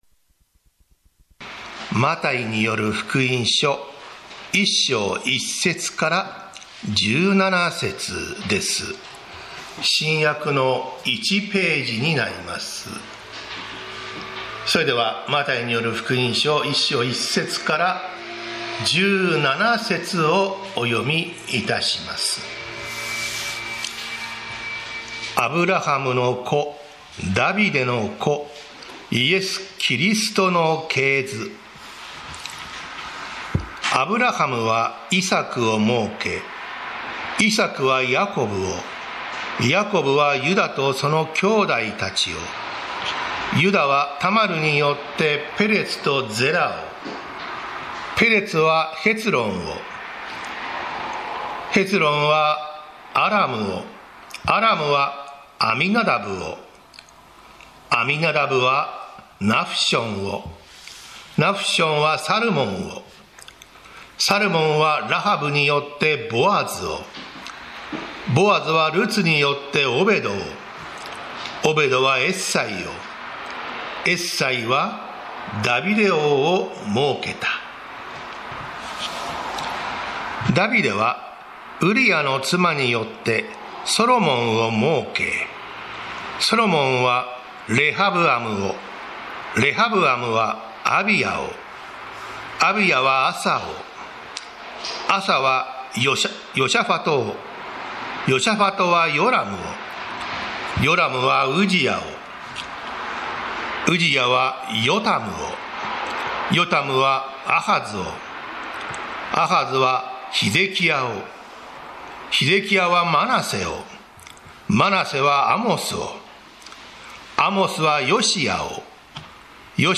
イエス・キリストとは誰なのか 宇都宮教会 礼拝説教